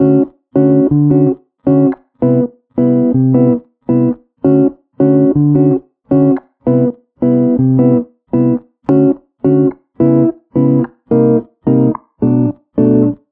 歩いてるギターが頭に浮かぶような、ギターのみのシンプルなループできるジングル。
オシャレ ギター まったり やさしい 爽やか